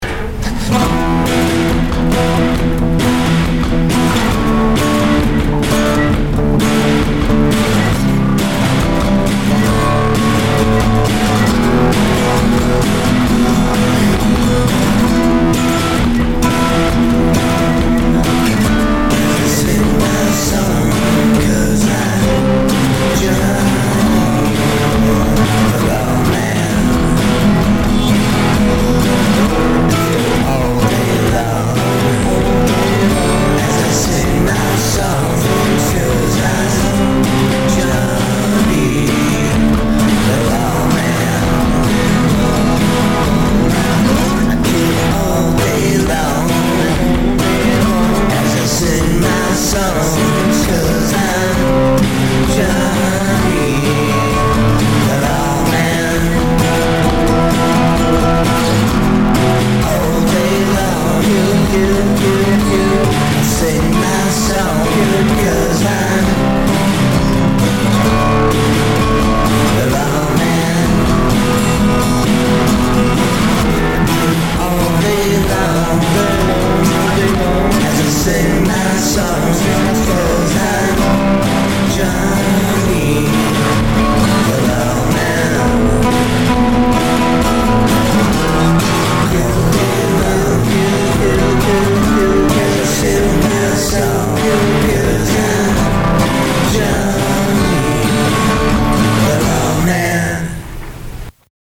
This is a quick, fun number he penned.